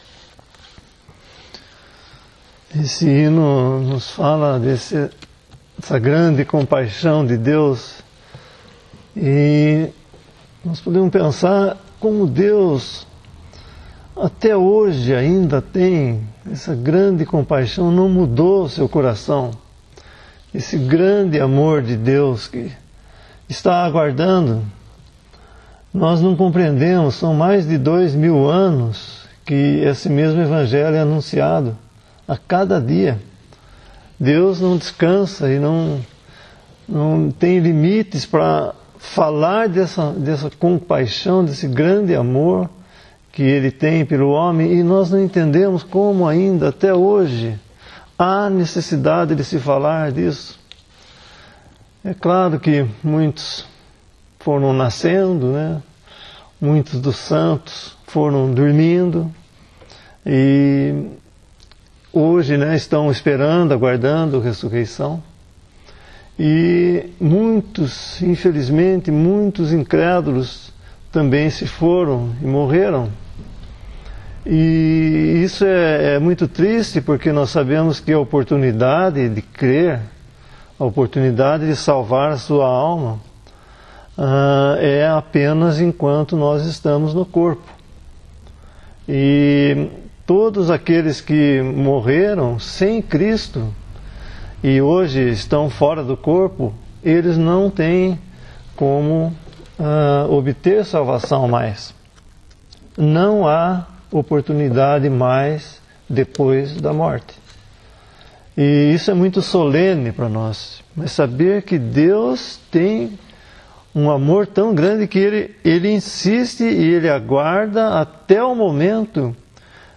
Arquivos em áudio com pregações do evangelho.